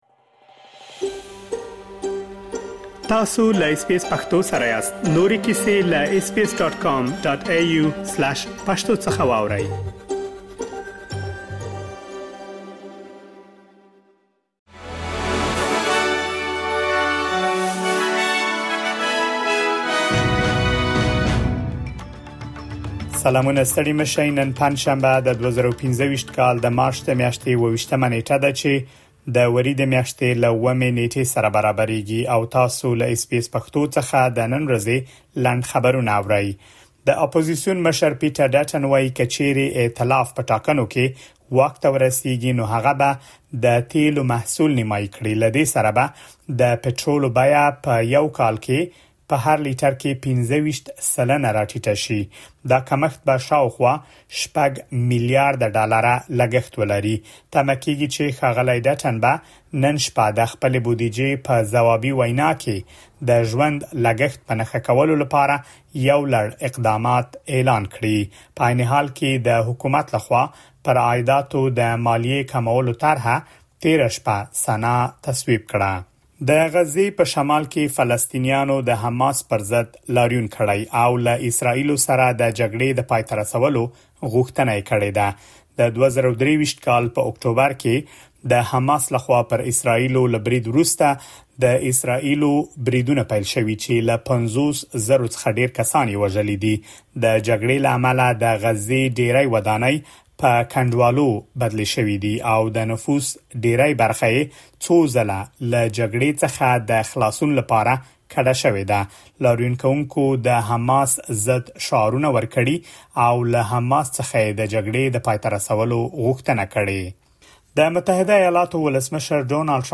د اس بي اس پښتو د نن ورځې لنډ خبرونه | ۲۷ مارچ ۲۰۲۵